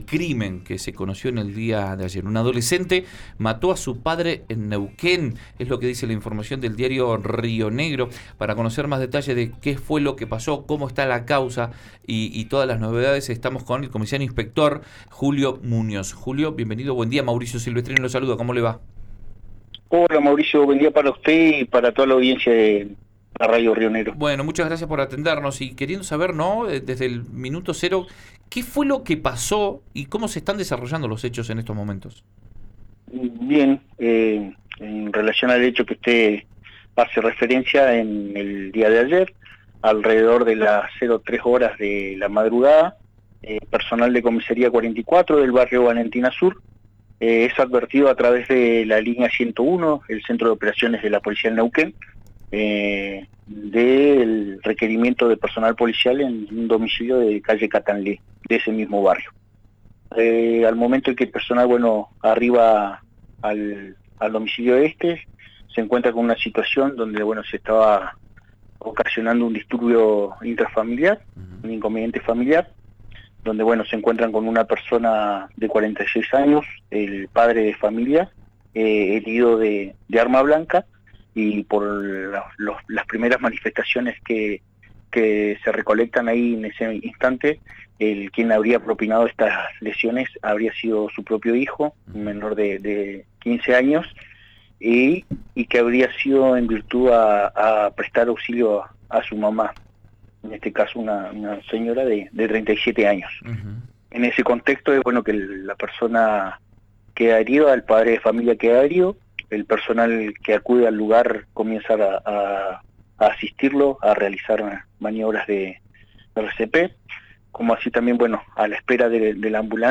Un comisario contó cómo surgió y se desarrolló el procedimiento.
en RÍO NEGRO Radio